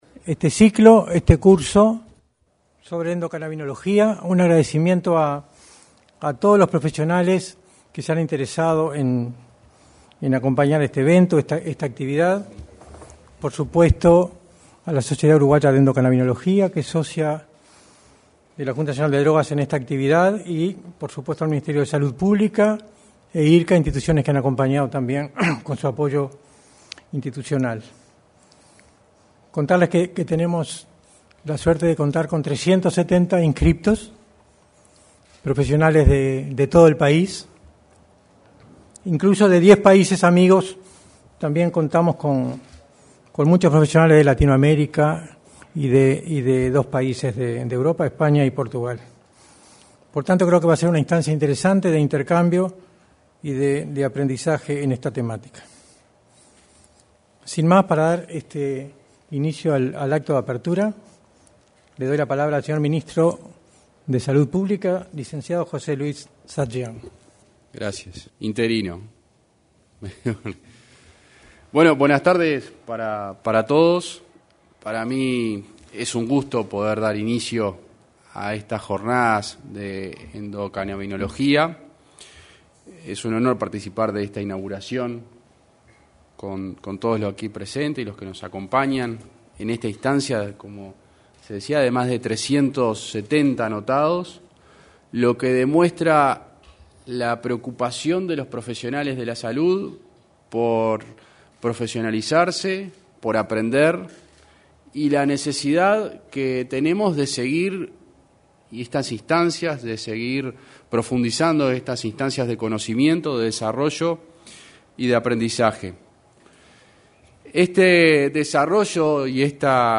Este jueves 19 de mayo, se realizó el lanzamiento del Curso de Endocannabinología en el salón de actos de la Torre Ejecutiva.
Your browser does not allow play this audio field.. 18 Minutos 30 Segundos - 6.05KB Descargar Archivo Enlaces relacionados Audios Declaraciones a la prensa del secretario general de la Junta Nacional de Drogas, Daniel Radío